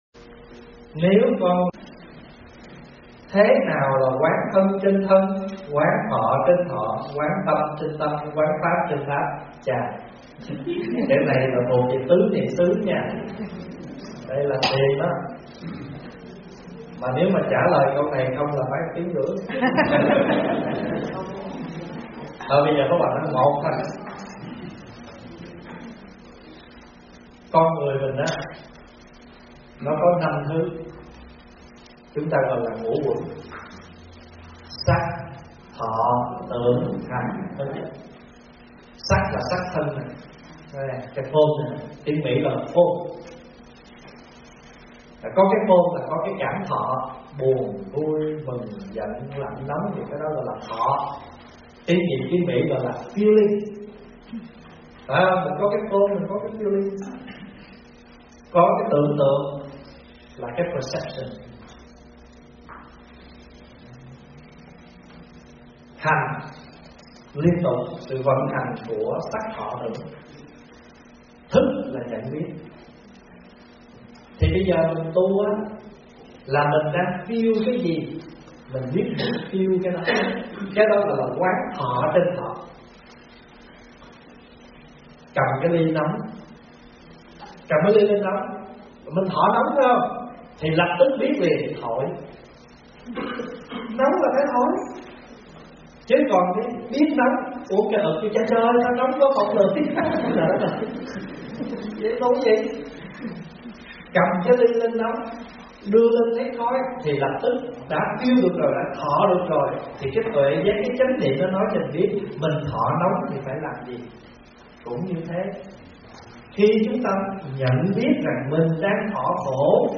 Nghe Mp3 thuyết pháp Ý nghĩa Ngũ Uẩn